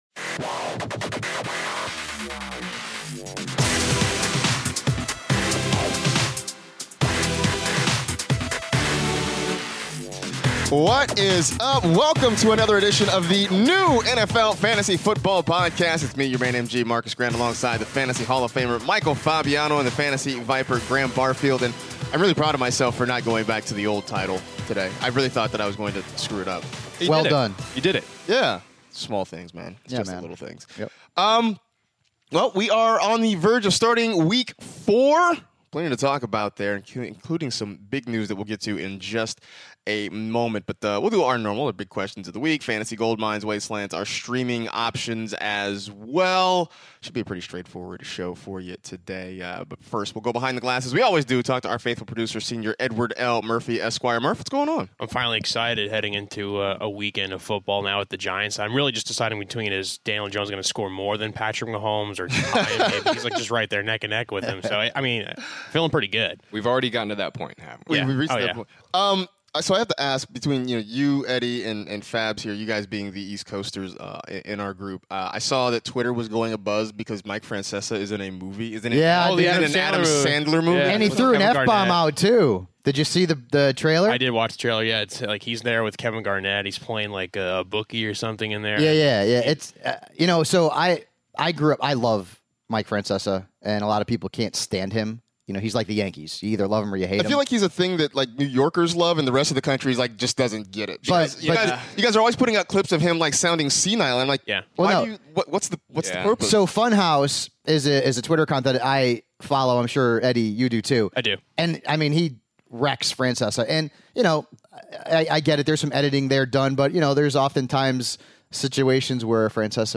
are back in the studio to preview the Week 4 matchups!